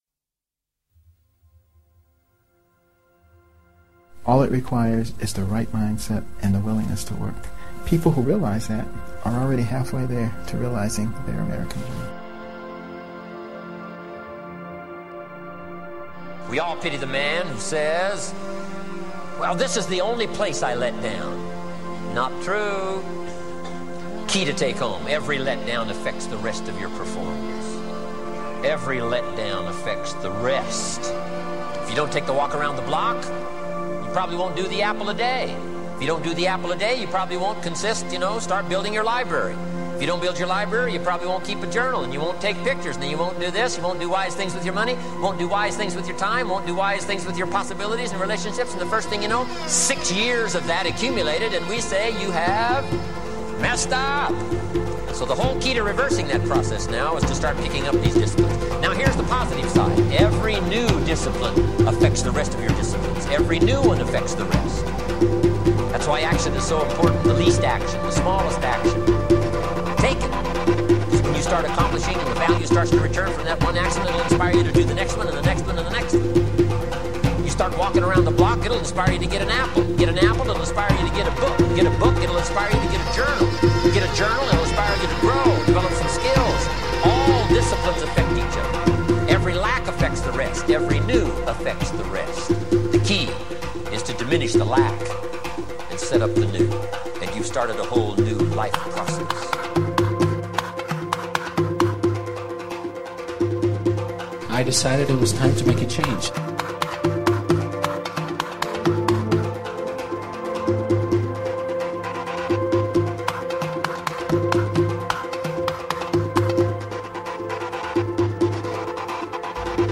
Many of the sounds, music and speech are mixed to create and associate feelings to certain things and activities.